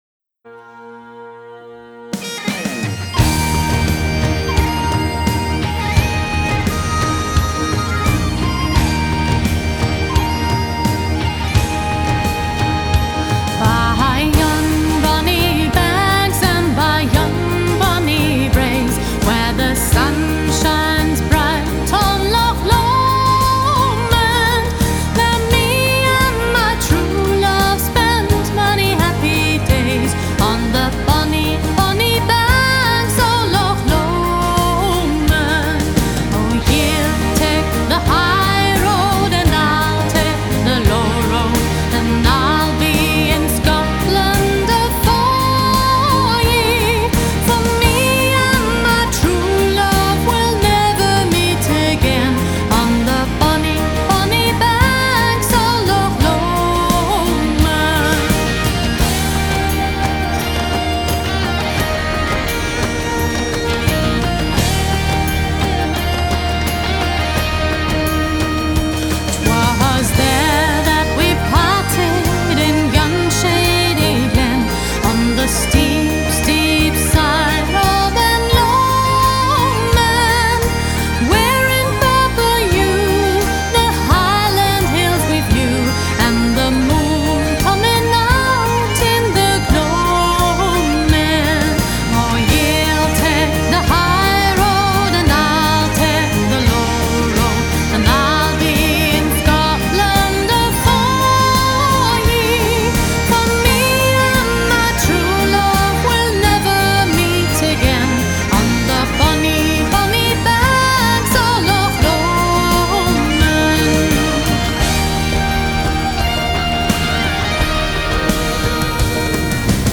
Keyboards, Guitar, Backing Vocals
Bagpipes, Guitar, Whistles, Backing Vocals